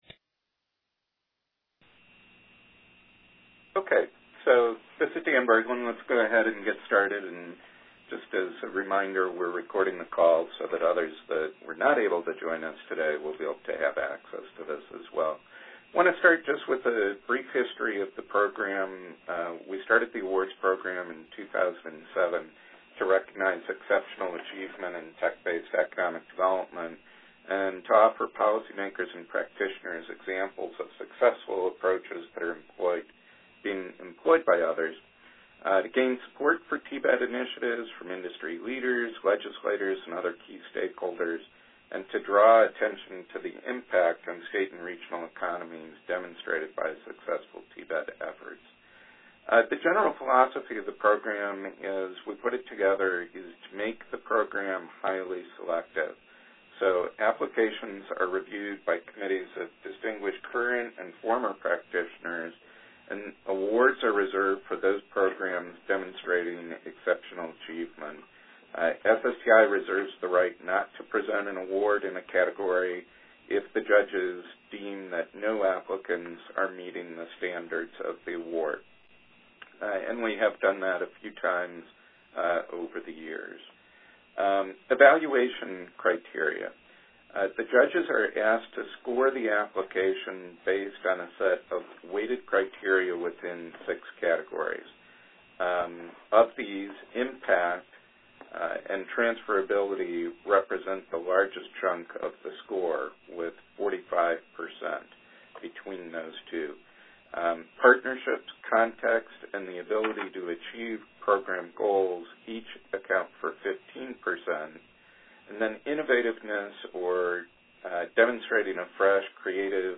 Finally, listen to a conference call for helpful tips.